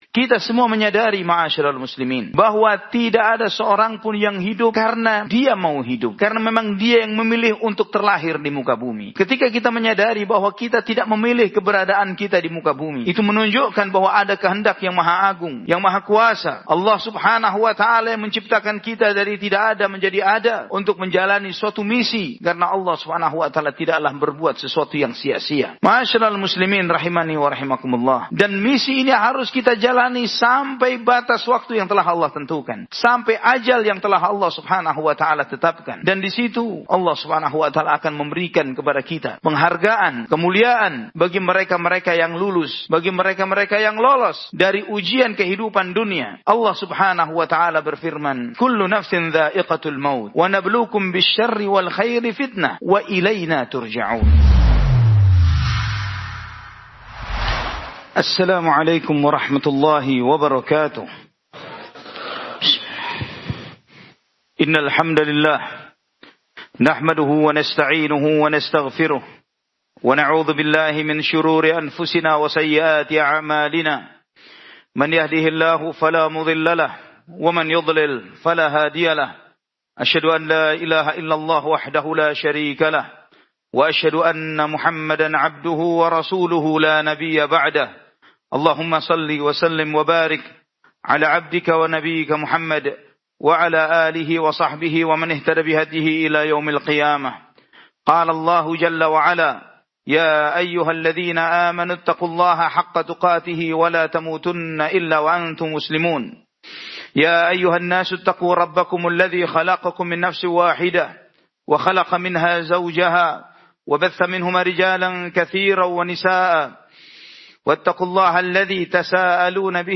Khutbah Jum'at